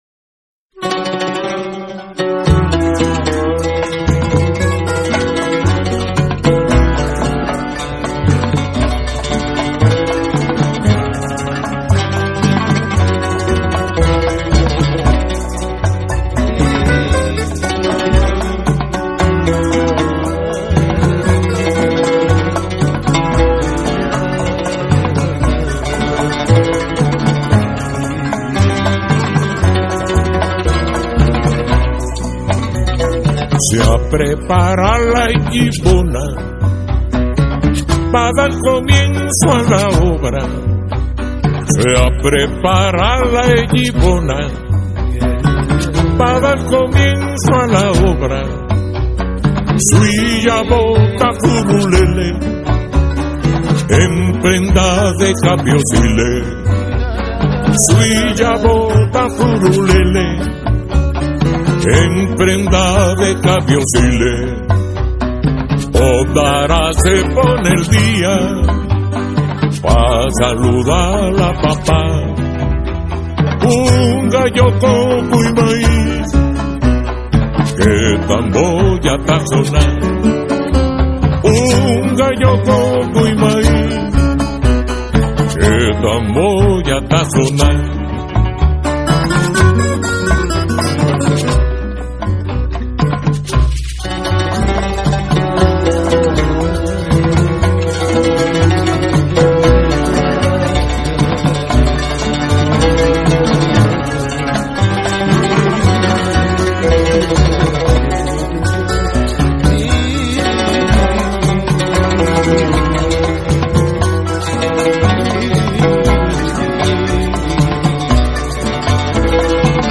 Son Cubano, Trova, Bolero